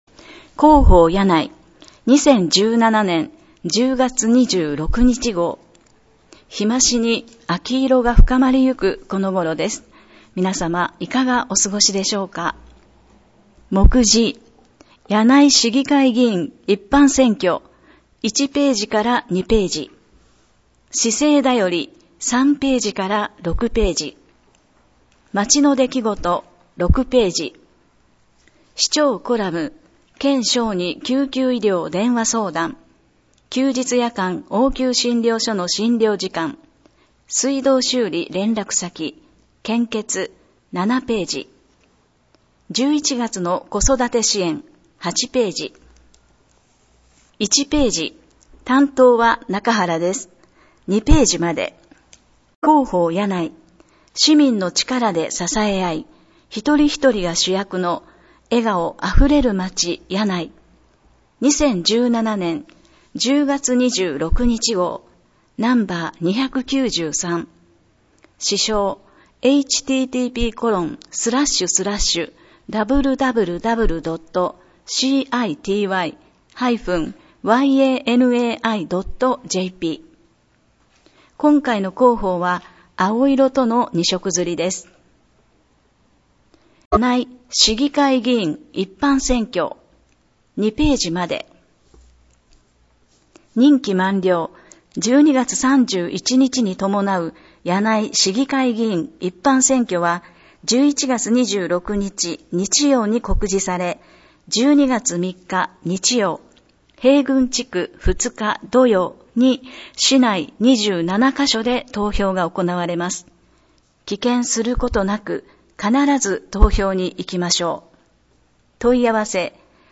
「音訳しらかべの会」の皆さんによる声の広報（音訳版広報）を、発行後1週間程度で掲載しています。